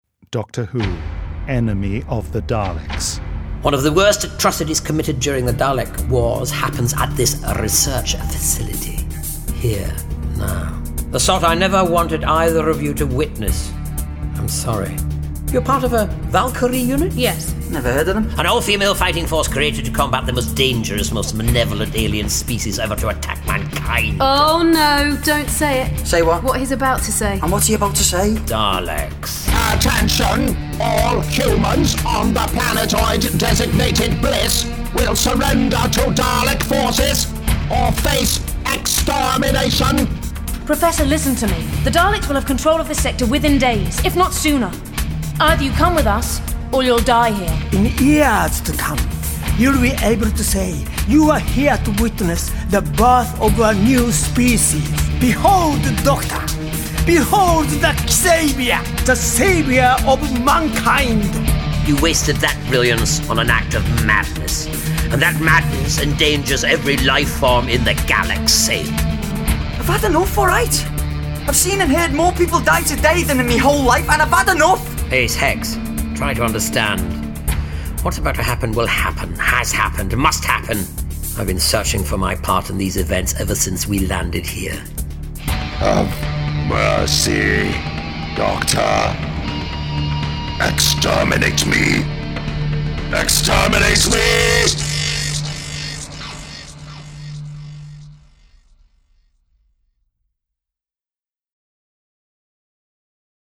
full-cast original audio dramas
Starring Sylvester McCoy Sophie Aldred